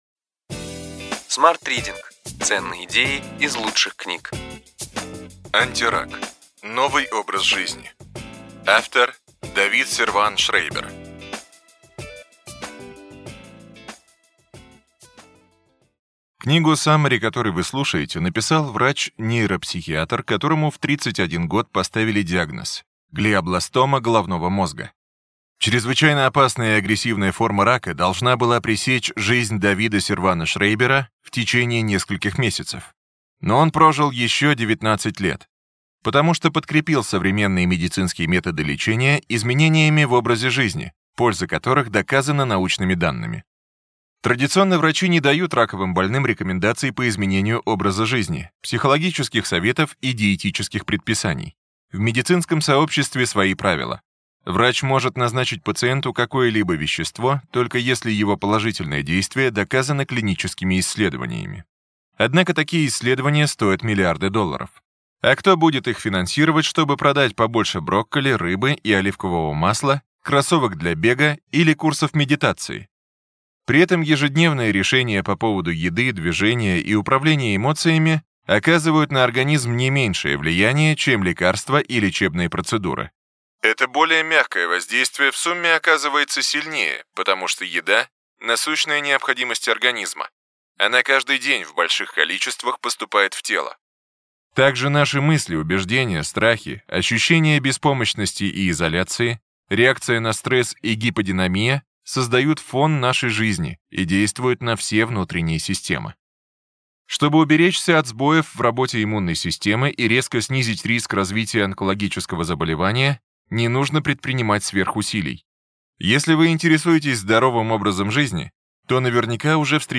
Аудиокнига Ключевые идеи книги: Антирак: новый образ жизни.
Прослушать и бесплатно скачать фрагмент аудиокниги